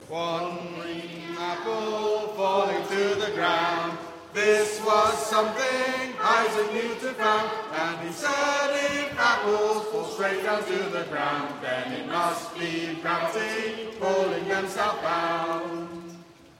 Singing History Concert 2016: One Green Apple 2
Lyrics composed by the CBBC's Horrible Histories song writer called Dave Cohen To the tune of children's nursery rhyme 'One Green Bottle'